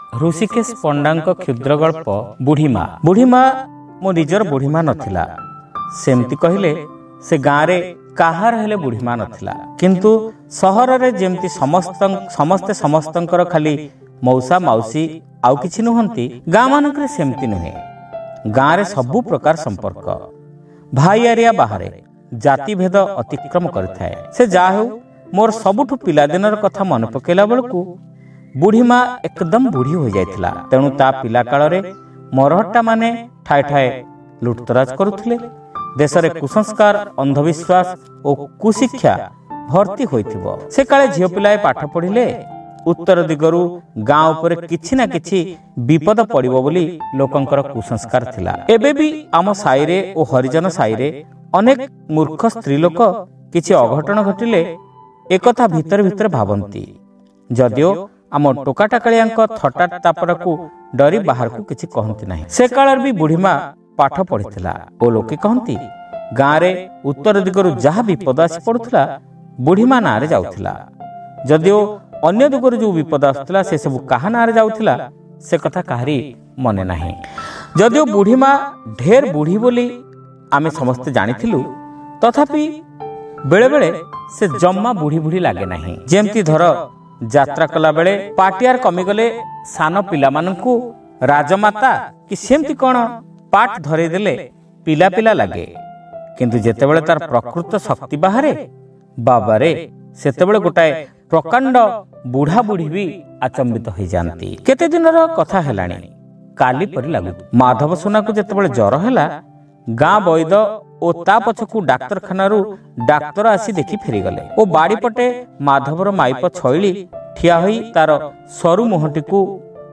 ଶ୍ରାବ୍ୟ ଗଳ୍ପ : ବୁଢ଼ୀ ମାଆ (ପ୍ରଥମ ଭାଗ)